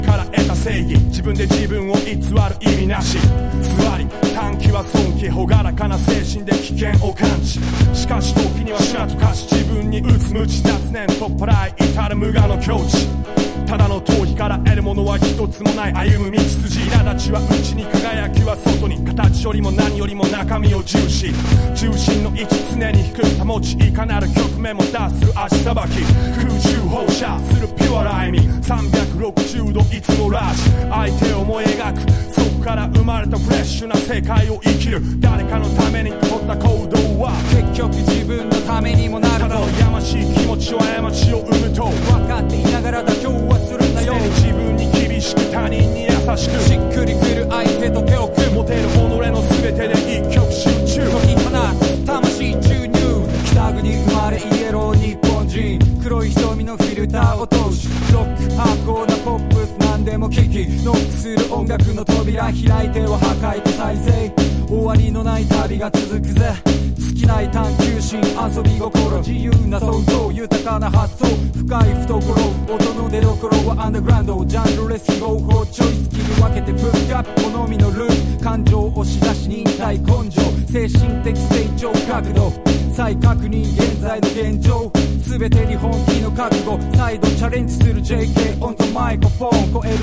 • HIPHOP
ダンサブル&ドラスティックな展開にシビれる06年のシングル！